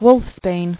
[wolfs·bane]